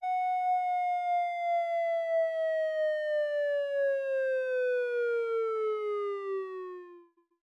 Siren Sound.wav